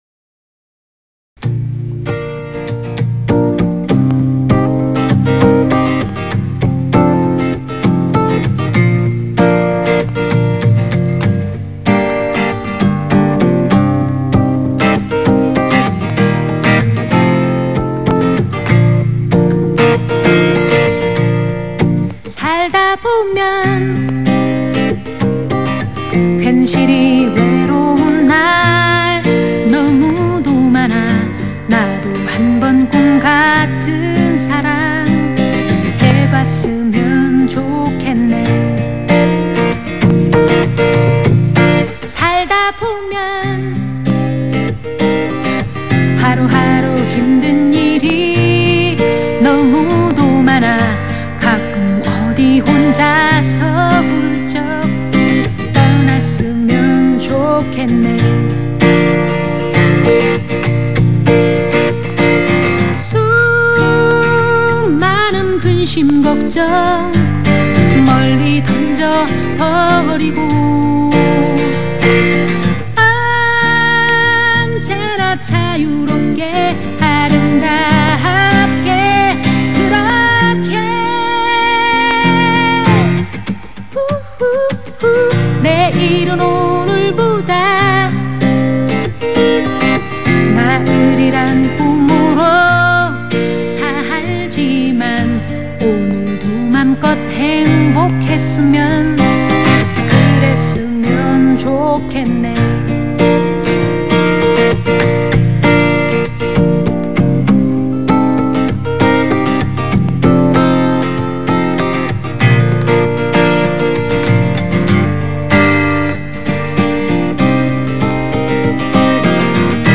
10년 가까이 언더그라운드에서 갈고닦은 여성 포크뮤지션